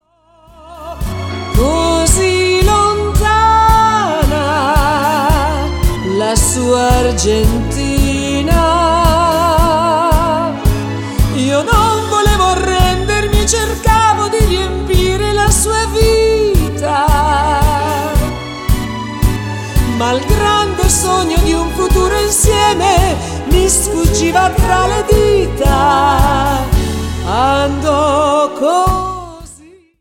TANGO  (03.47)